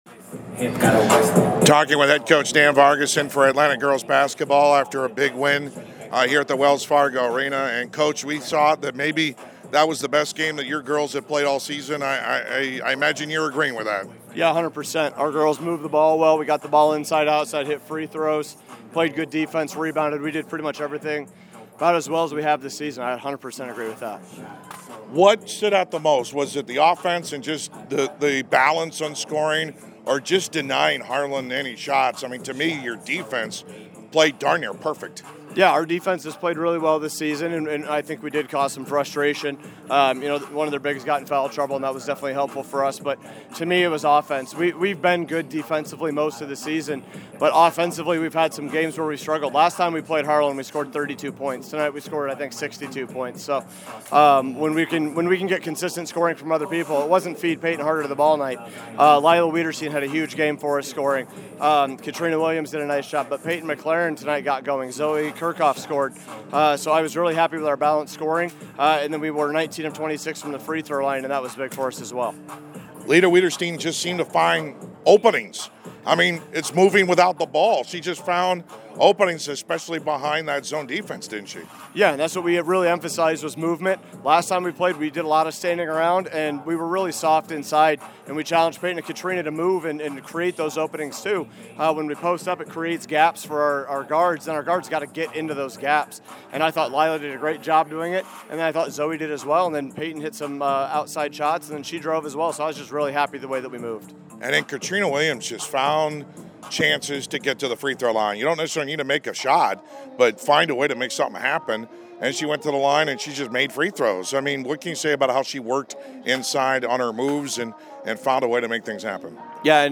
Post Game Interview